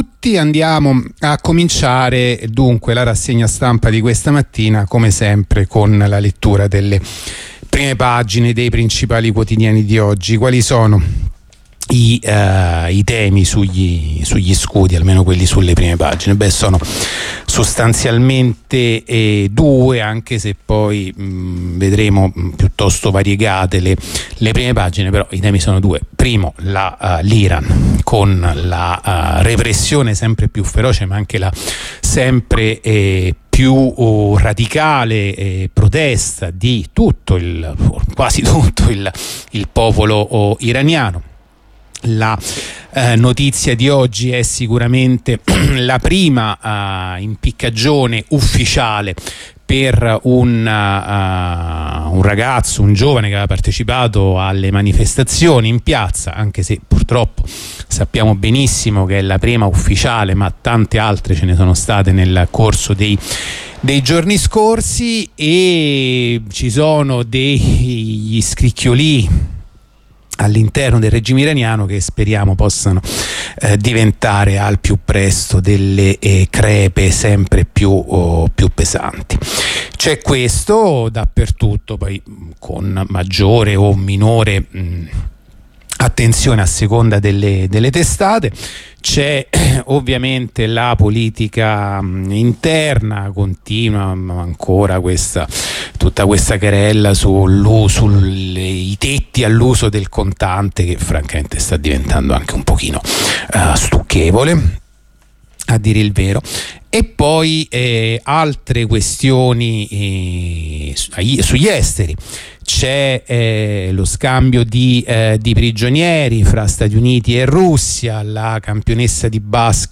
La rassegna stampa di radio onda rossa andata in onda venerdì 9 dicembre 2022